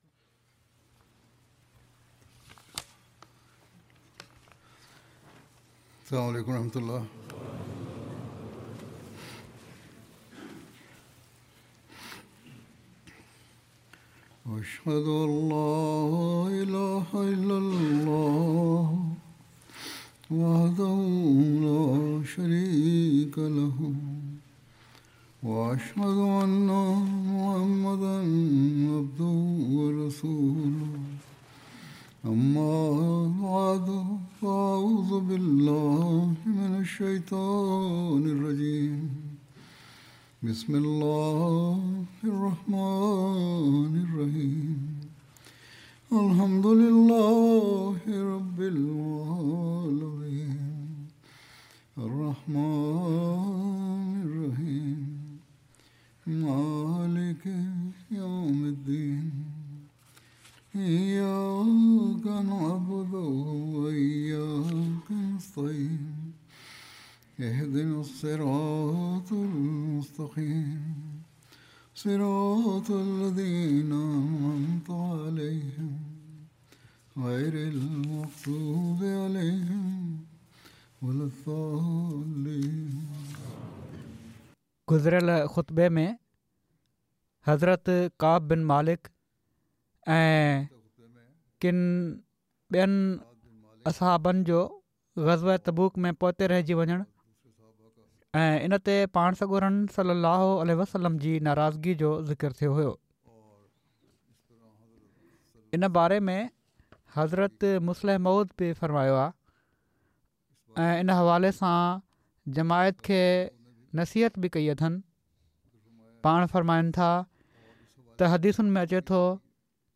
Sindhi Translation of Friday Sermon delivered by Khalifatul Masih